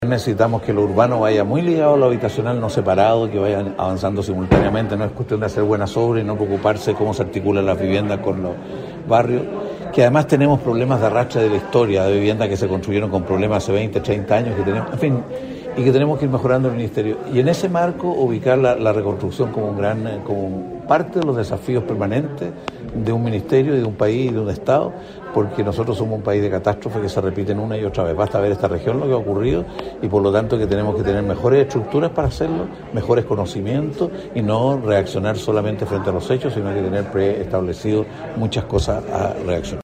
En tiempos de resiliencia y reconstrucción: Desafíos del desarrollo urbano y rural para el Chile del 2050 es el título del seminario organizado por la Asociación Chilena de Municipalidades y que se desarrollará en la Universidad del Bío-Bío hasta este viernes.
Carlos Montes, ministro de Vivienda y Urbanismo, fue el expositor central de la primera jornada.